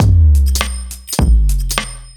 OTG_Kit6_Wonk_110a.wav